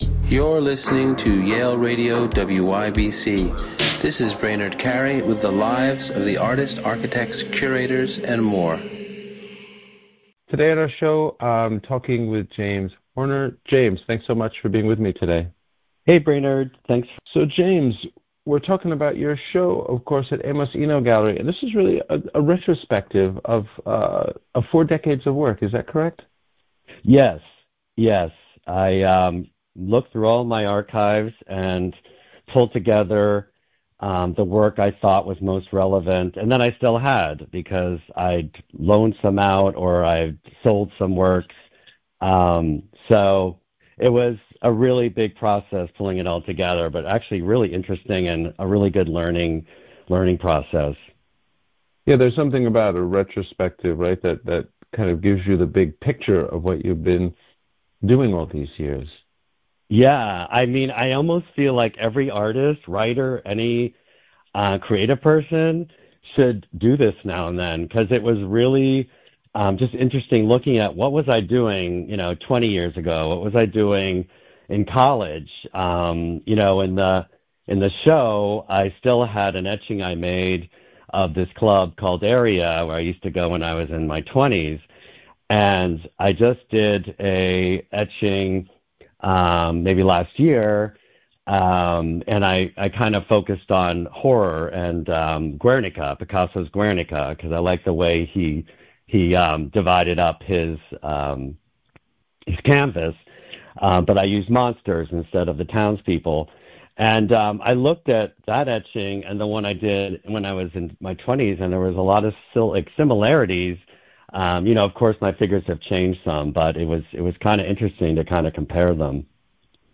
Interviews from Yale University Radio WYBCX